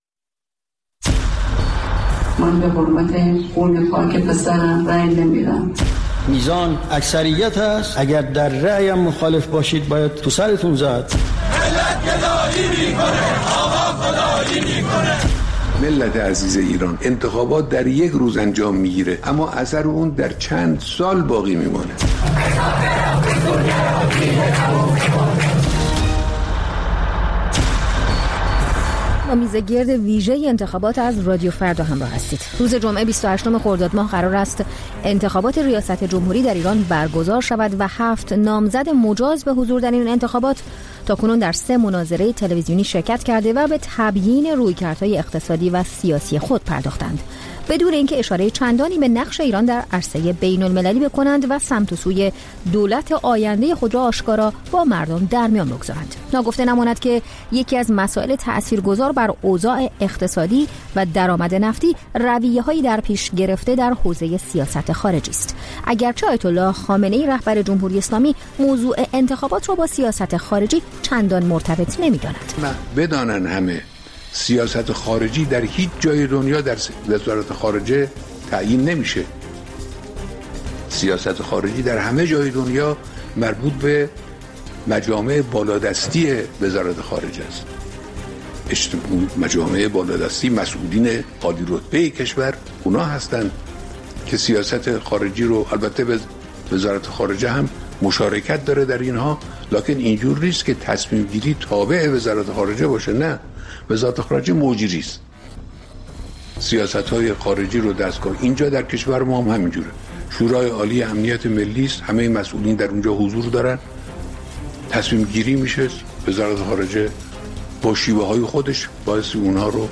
میزگرد ویژه انتخابات: نقش سیاست خارجی در انتخابات